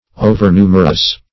Overnumerous \O"ver*nu"mer*ous\, a. Excessively numerous; too many.